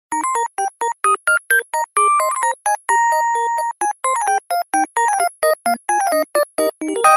Toy_Doll.mp3